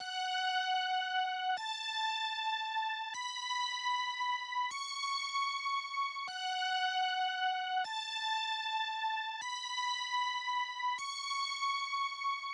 シンセパッド
ハリのあるストリングスのような音ですね。
アタック感も弱いため特に目立たないパートです。